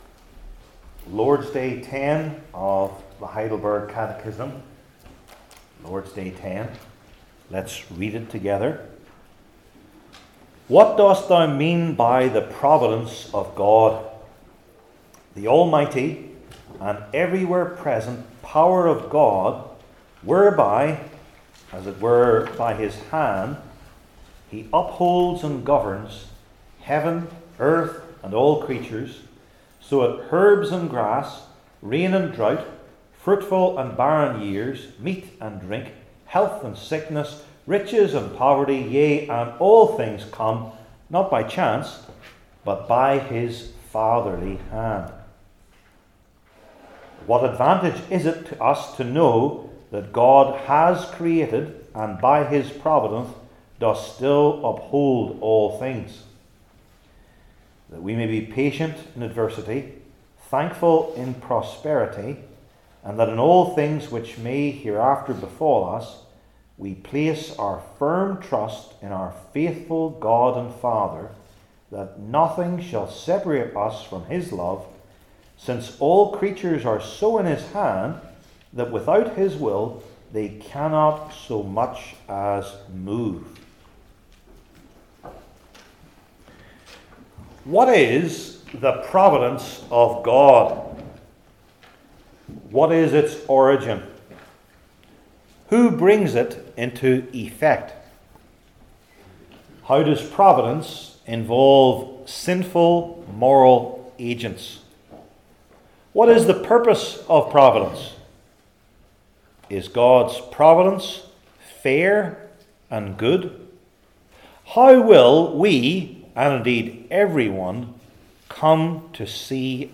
6:9-17 Service Type: Heidelberg Catechism Sermons I. Its Meaning and Its Source II.